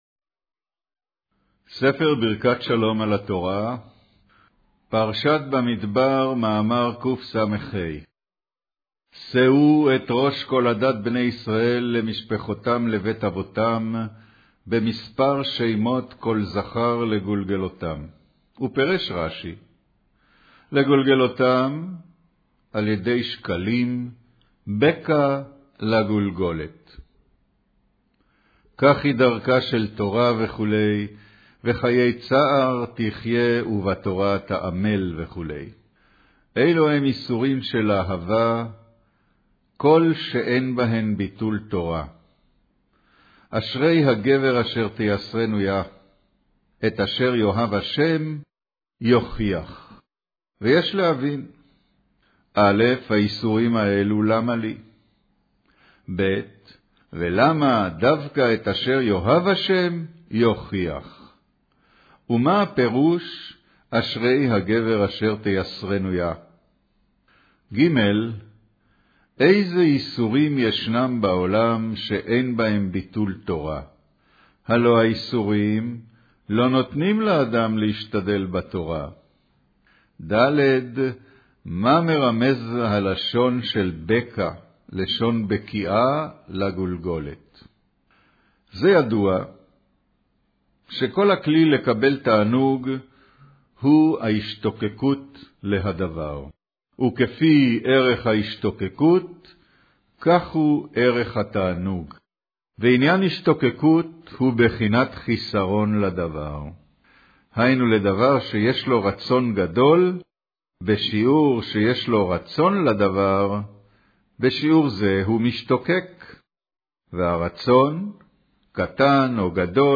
אודיו - קריינות פרשת במדבר, מאמר שאו את ראש